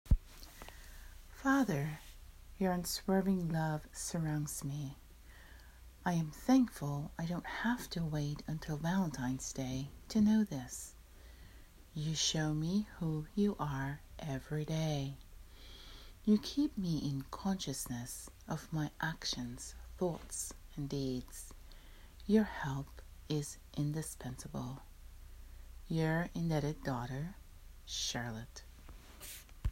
My prayer:
Listen to me read this prayer: